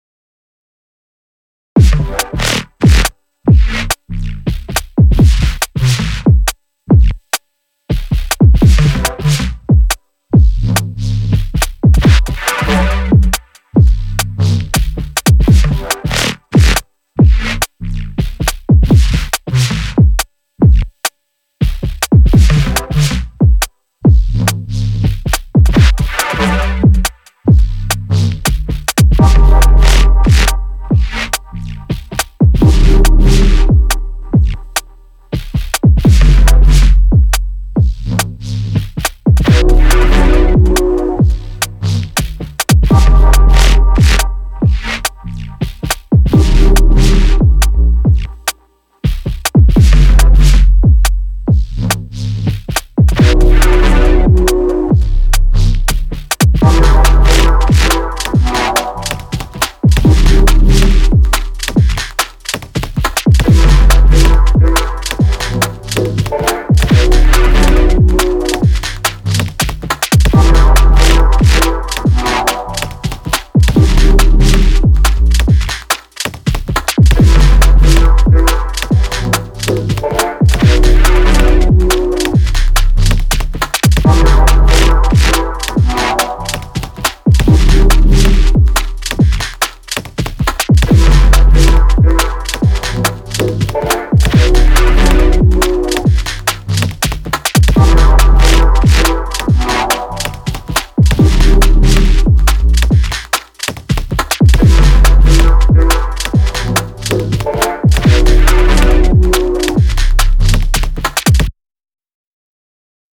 Denoiser Pro came in good. Could be better but overall did the job
it’s running only trough right channel.